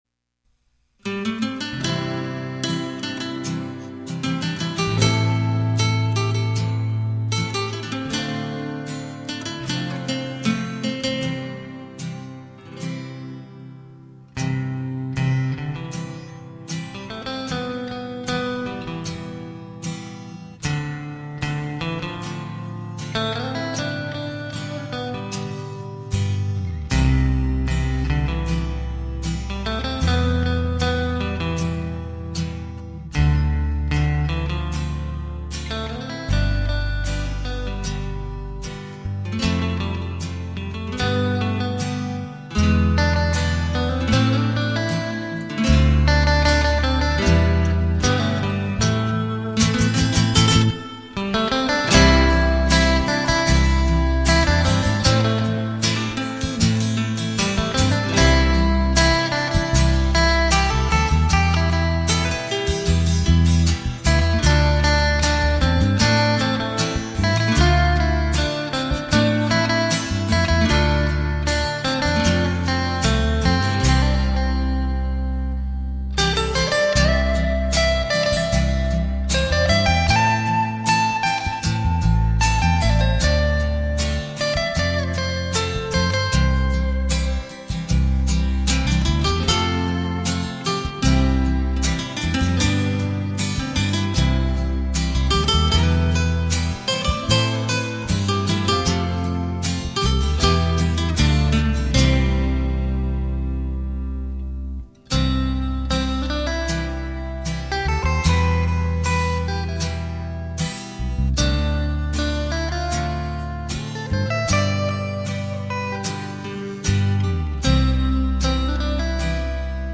畅销东洋演歌名曲演奏